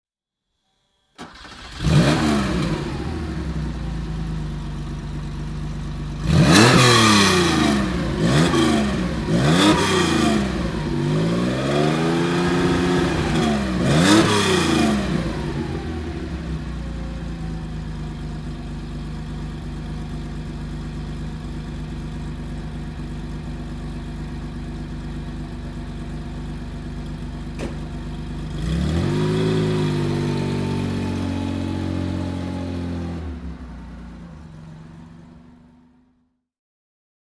Porsche Carrera RS 2.7 (1973) - Starten und Leerlauf